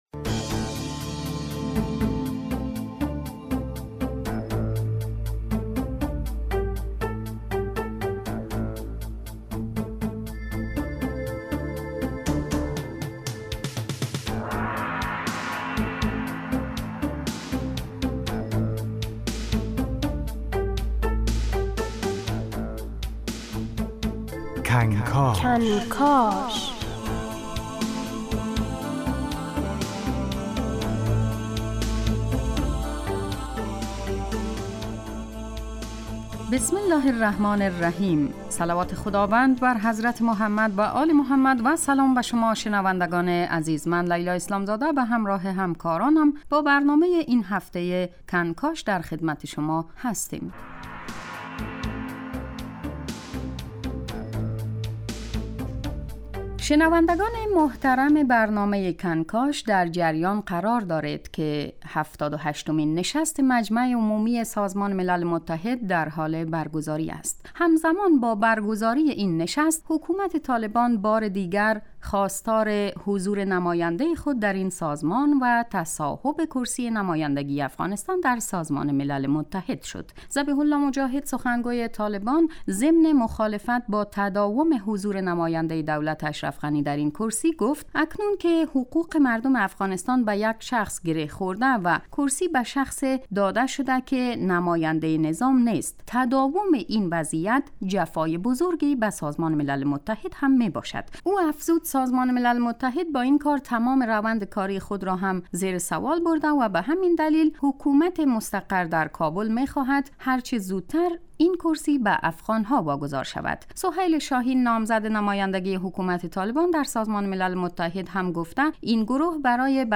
برنامه از تهران تا کابل به مدت 20 دقیقه روز پنجشنبه در ساعت 09:40 صبح و تکرار آن در ساعت 17:40 (به وقت افغانستان) پخش می شود. این برنامه به بررسی مهمترین رخدادهای افغانستان در حوزه های مختلف و به ویژه سیاسی می پردازد.